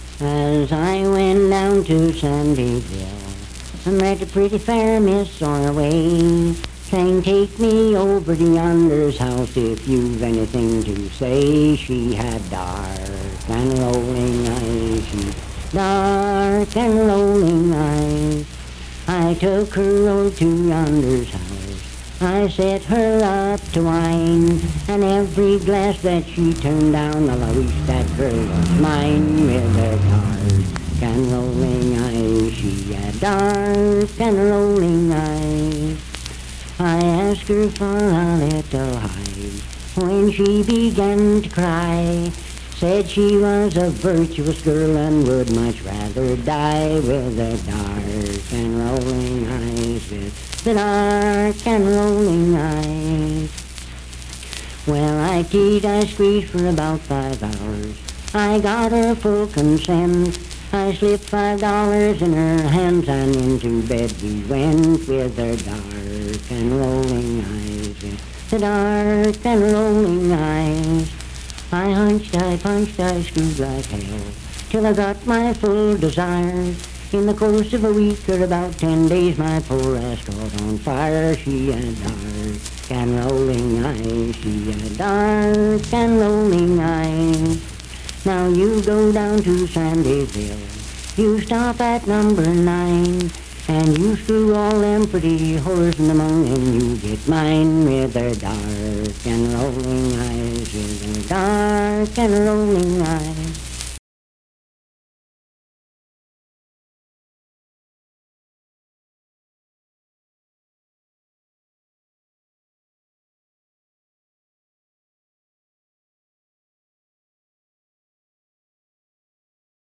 Unaccompanied vocal music
Performed in Sandyville, Jackson County, WV.
Bawdy Songs
Voice (sung)